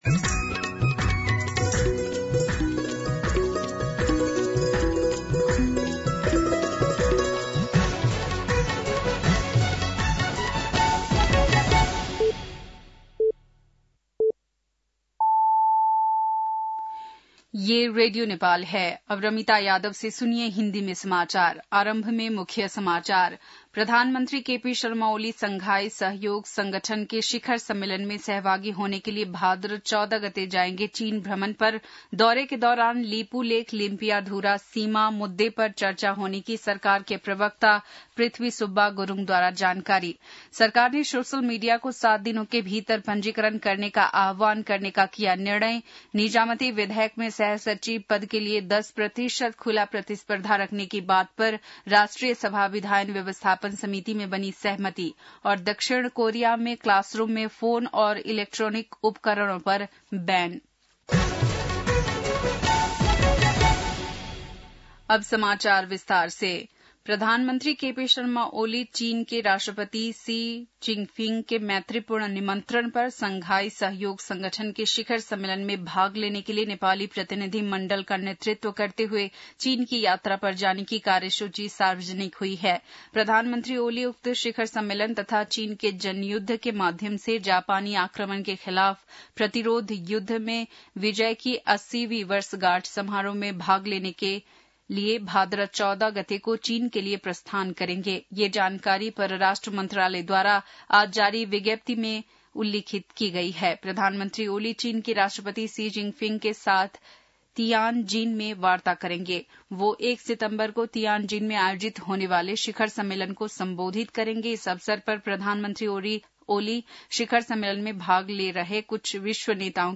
बेलुकी १० बजेको हिन्दी समाचार : ११ भदौ , २०८२